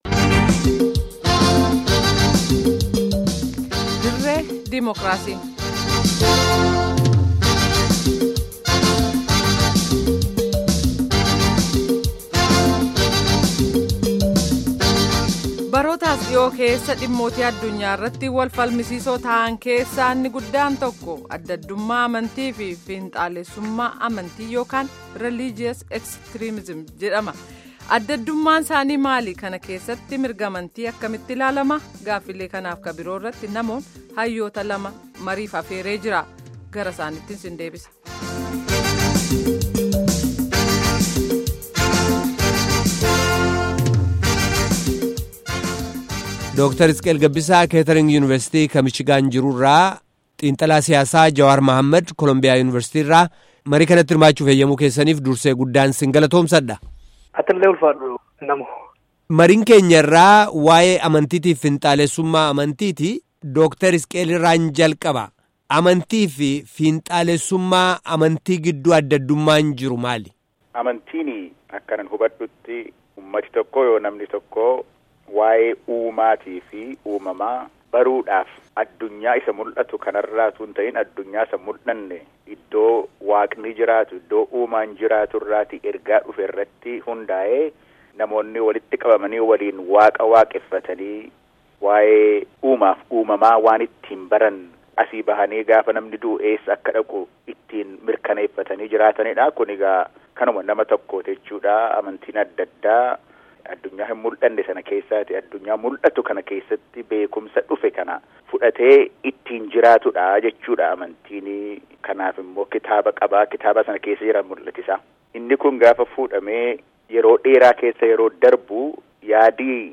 Guutummaa gaaffii fi deebii kanaa dhaggeeffadhaa